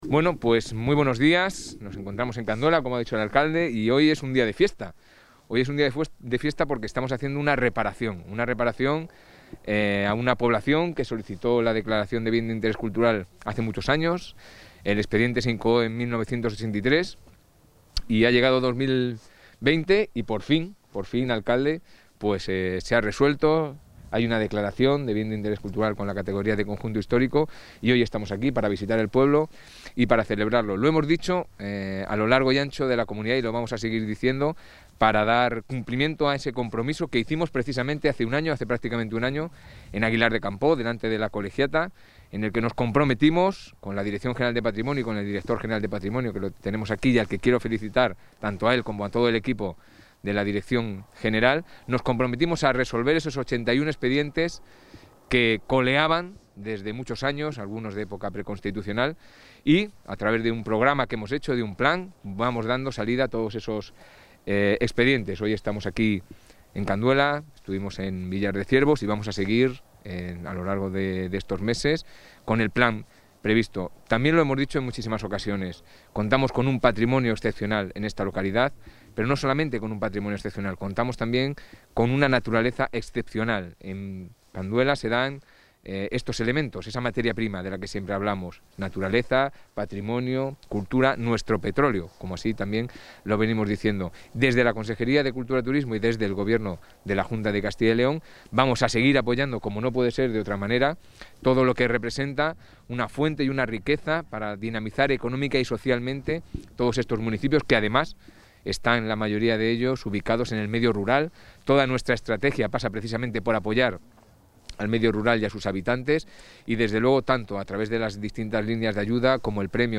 El consejero de Cultura y Turismo, Javier Ortega, ha visitado hoy la localidad palentina de Canduela, junto con la alcaldesa de Aguilar de Campoo,...
Intervención del consejo de Cultura y Turismo.